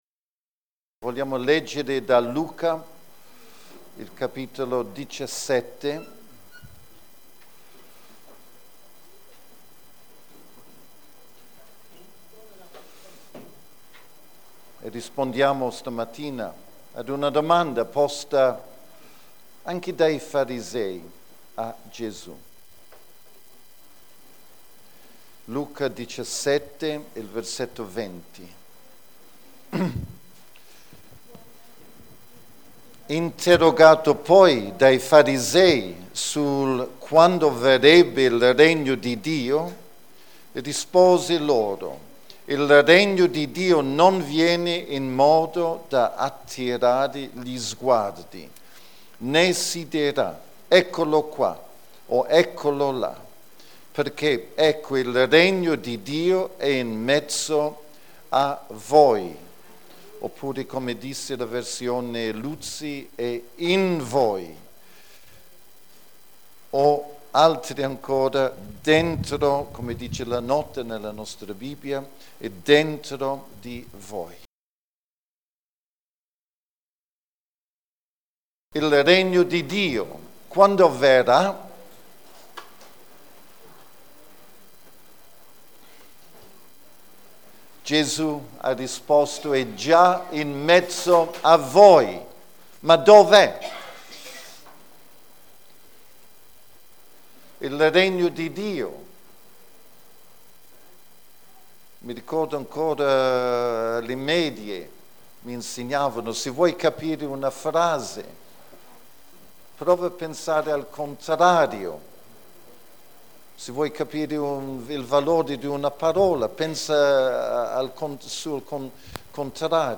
Predicazione 23 agosto 2015 - Venga il Tuo Regno o Signore!